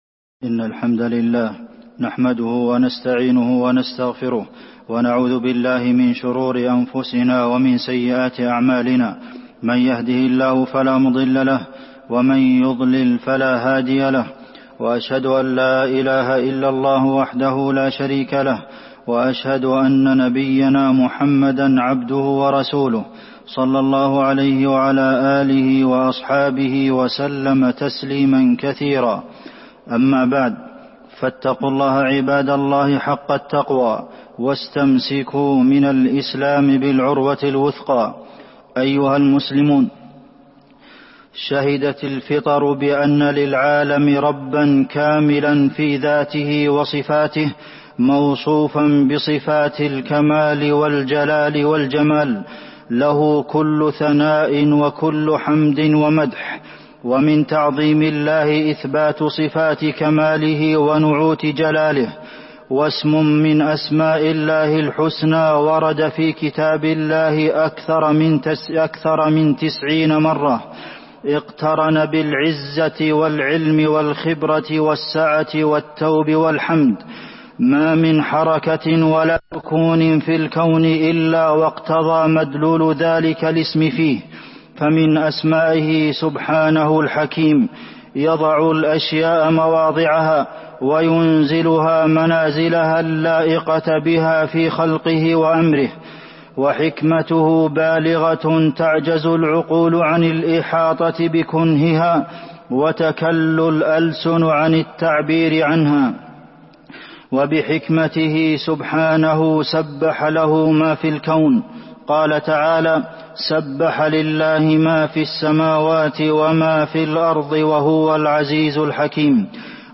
تاريخ النشر ١٣ شوال ١٤٤١ هـ المكان: المسجد النبوي الشيخ: فضيلة الشيخ د. عبدالمحسن بن محمد القاسم فضيلة الشيخ د. عبدالمحسن بن محمد القاسم اسم الله الحكيم